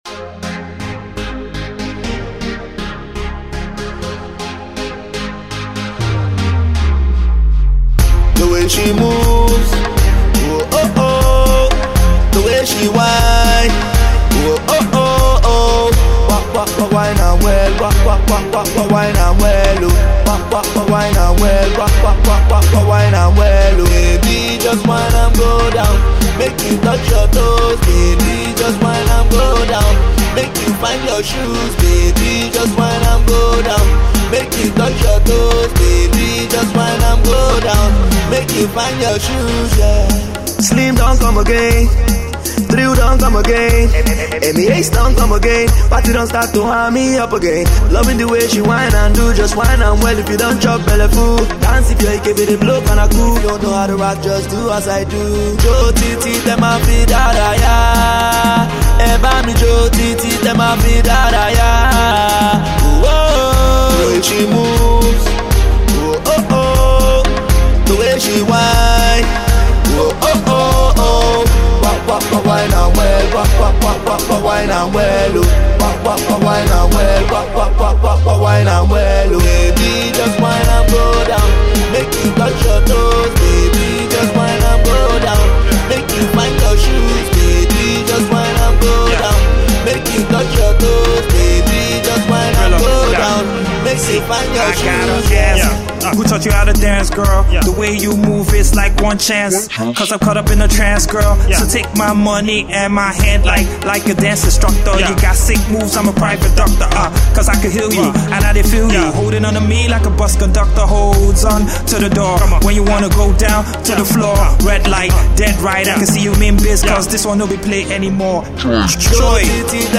The song is super catchy, entertaining, dance-able